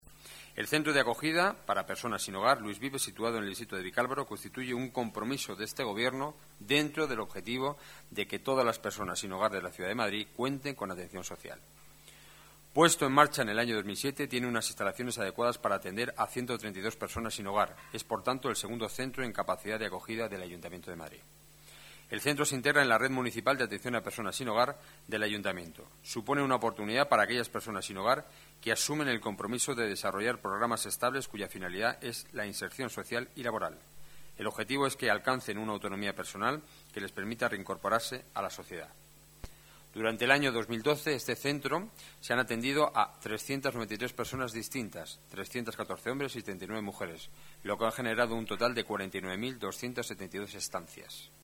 Nueva ventana:Declaraciones portavoz Gobierno municipal, Enrique Núñez: prórroga contrato centro Luis Vives